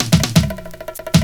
FILL 8    -L.wav